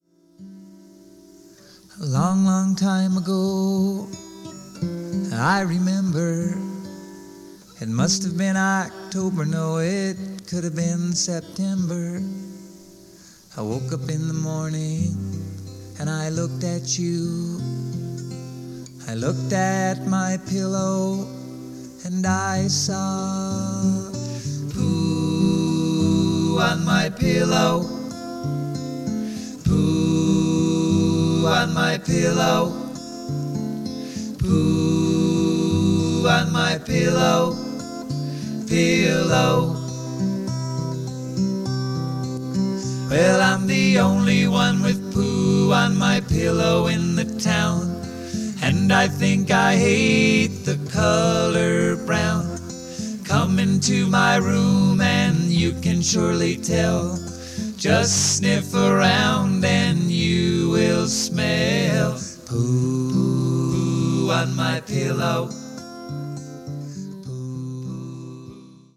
on an old Teac 4-track, reel-to-reel recorder.